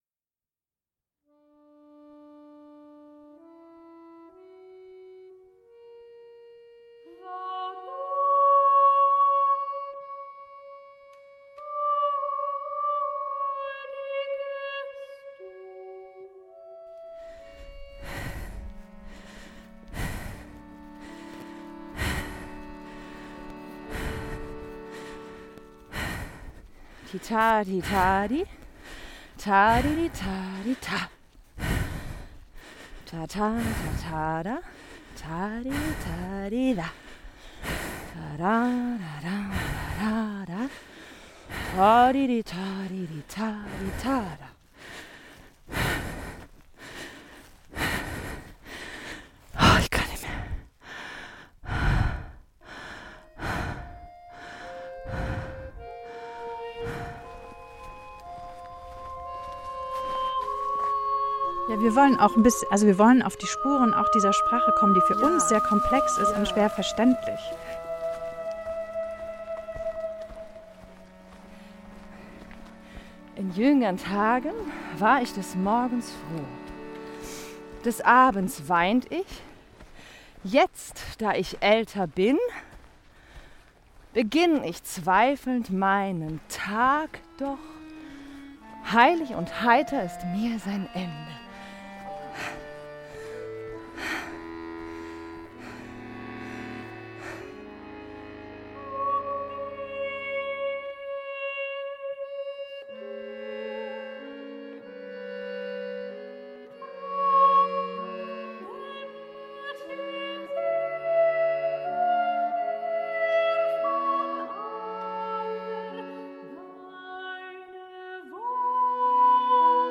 Ein Konzertabend auf Spuren Hölderlins
eine Singstimme, ein Akkordeon, eine Sprecherstimme, Audio-Einspielungen;
Künstlerkollektiv Akrotesque
Hölderlin-Trailer-Konzertabend.mp3